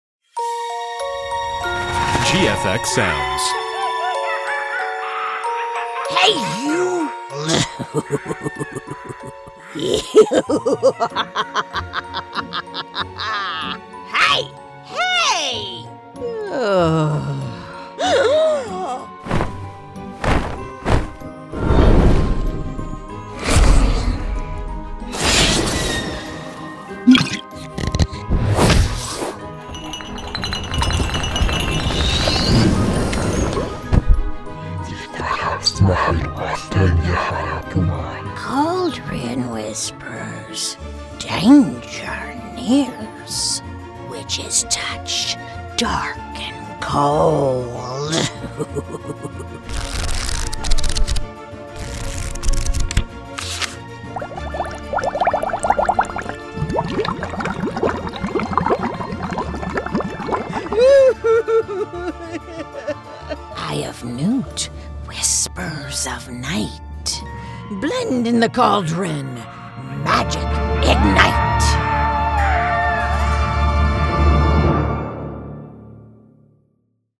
witchcraft sound effects
SFX Pack – Demo. (Please note that all audio watermarks will be removed on download)
witchcraft-sfx-pack-preview.mp3